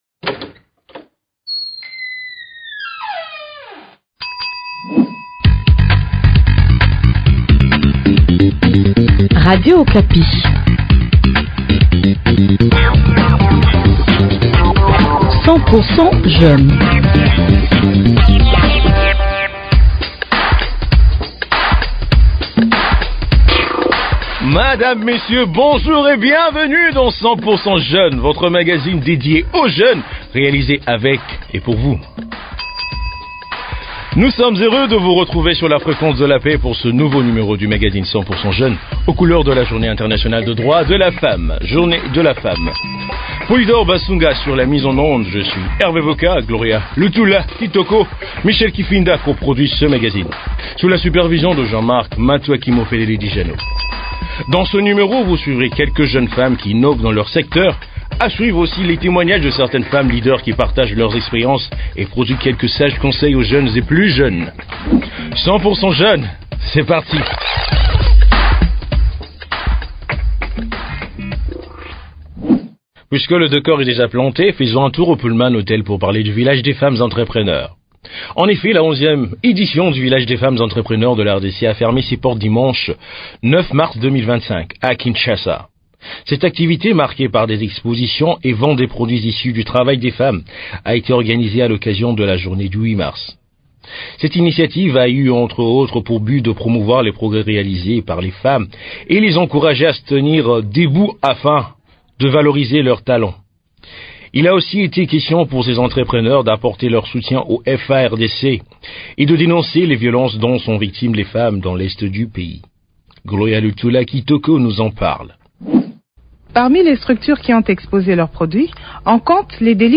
Dans ce numéro vous suivrez quelques jeunes femmes qui innovent dans leurs secteurs, à suivre aussi les témoignages de certaines femmes leaders qui partagent leurs expériences et prodiguent quelques sages conseils aux jeunes et plus jeunes.